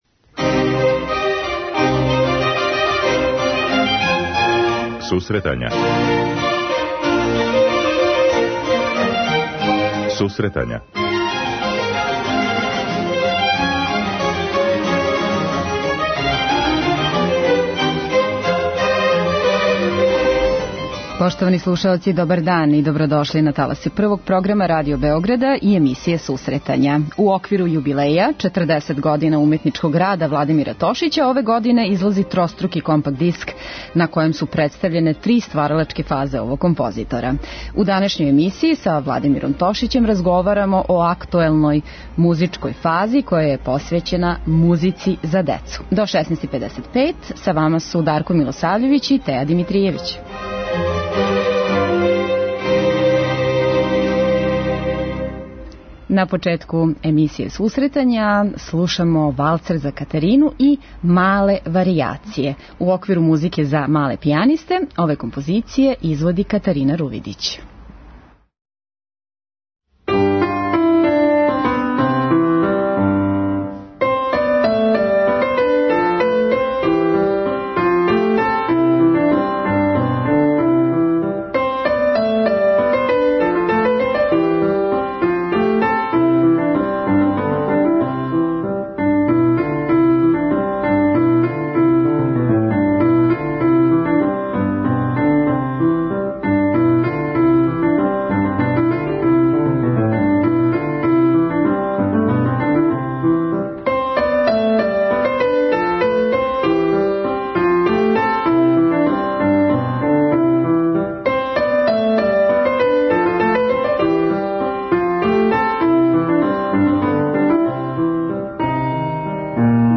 У данашњој емисији разговарамо о актуелној музичкој фази која је посвећена музици за децу.